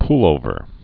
(plōvər)